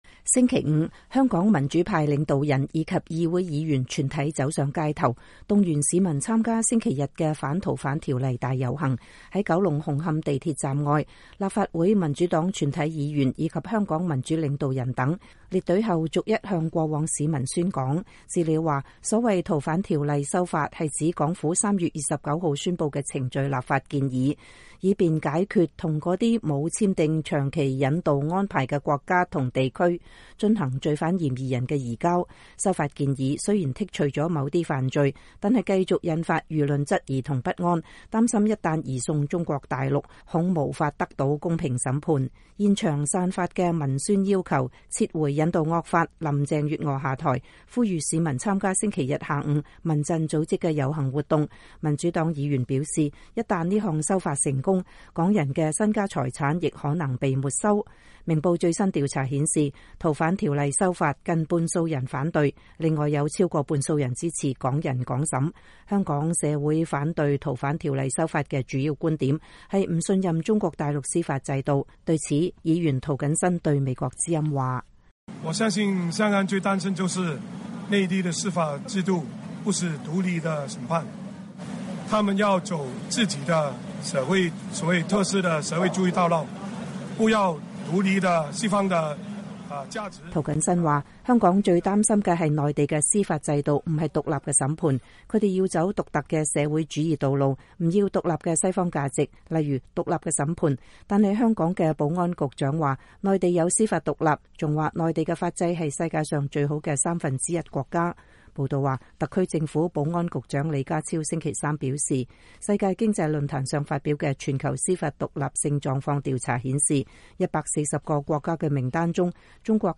在九龍紅磡地鐵站外，立法會民主黨全體議員以及香港民主領導人等，列隊後逐一向過往市民宣講。